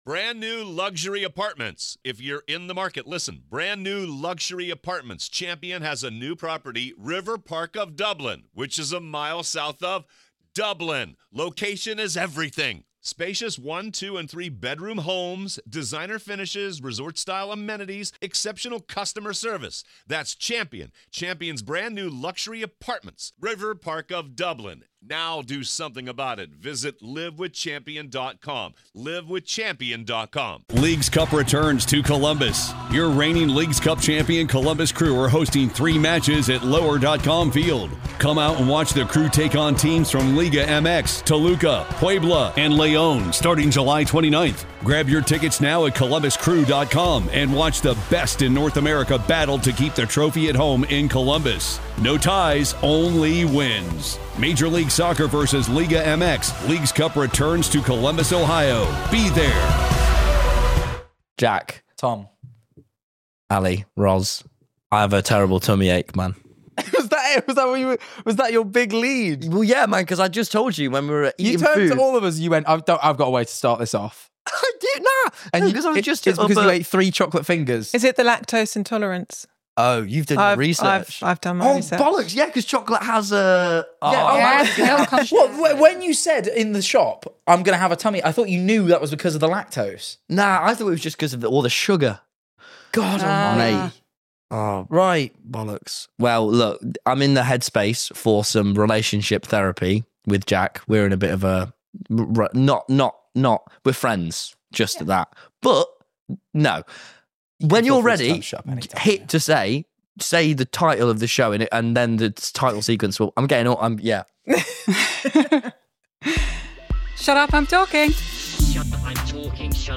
Written & presented by: Tom Simons & Jack Manifold